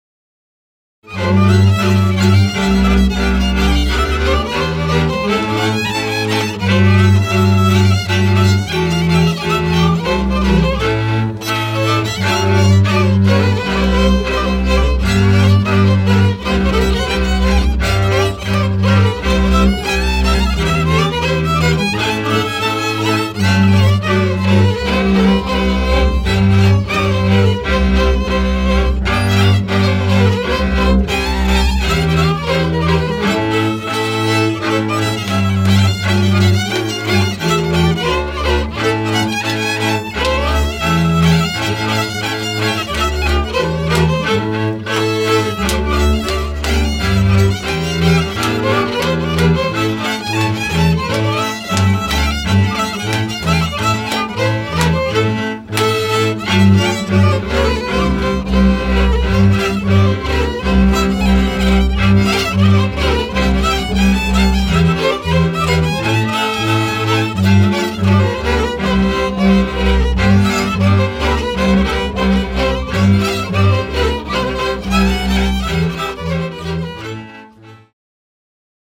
hegedű
kontra
bőgő